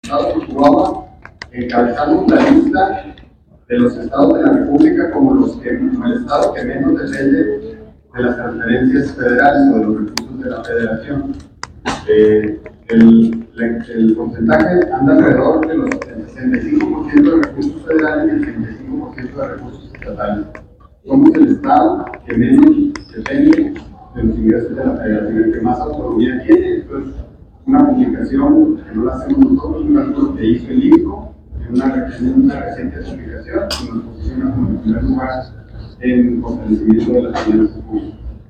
AUDIO: JOSÉ DE JESÚS GRANILLO, SECRETARIO DE HACIENDA ESTATAL 1